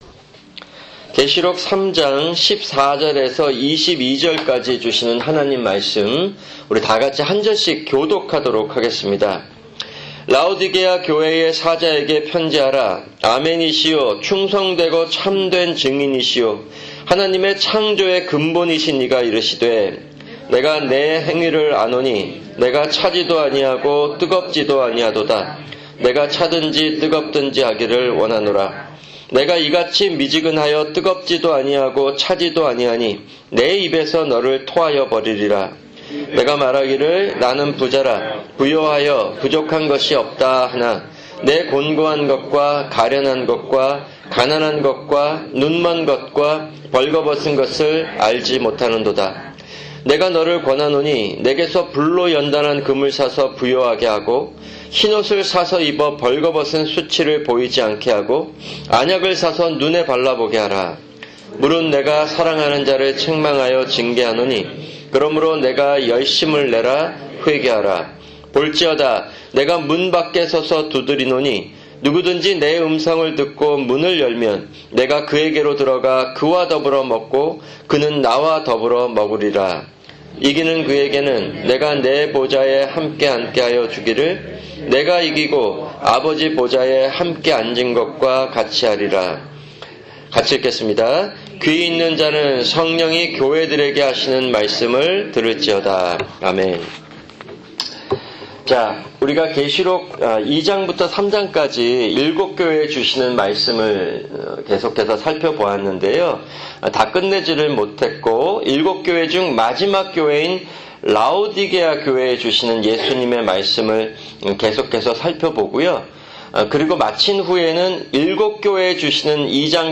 [금요 성경공부] 일곱 교회(26) 계3:14-22(5)